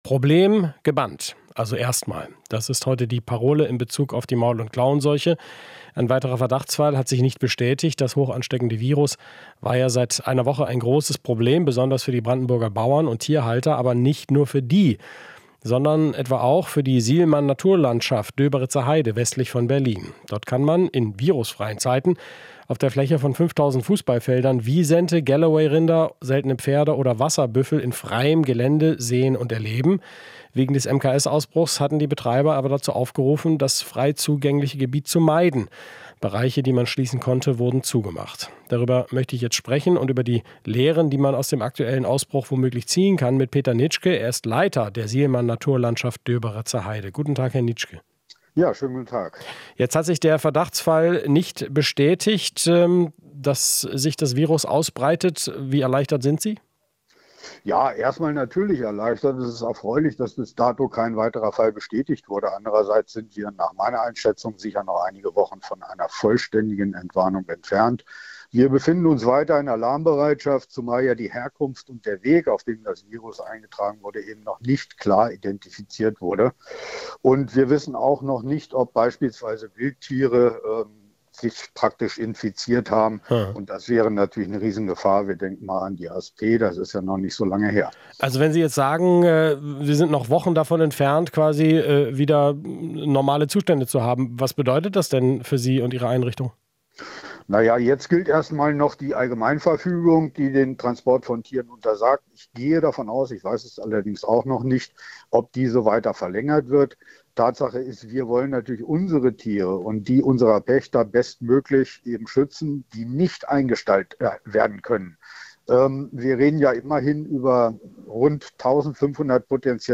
Interview - Angst vor MKS - auch im Naturpark Döberitzer Heide